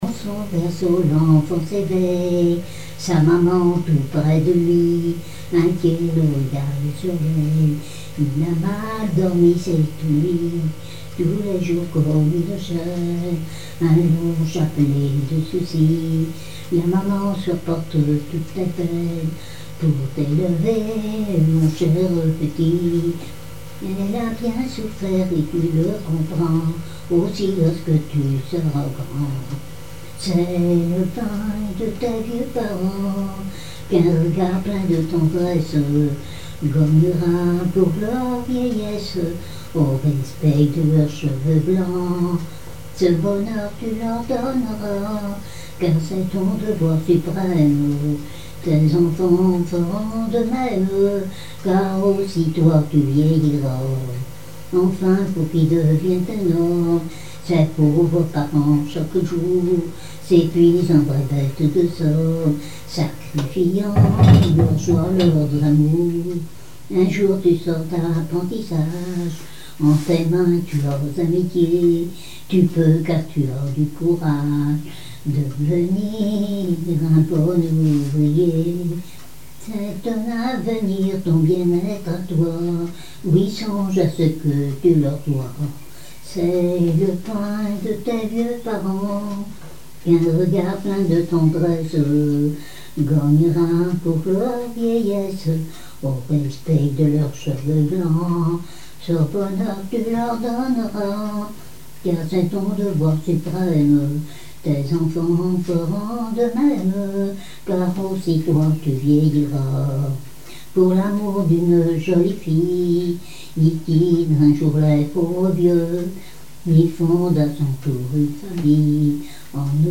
Chansons et témoignages
Pièce musicale inédite